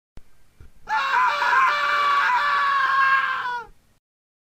男人疯狂尖叫声音效免费音频素材下载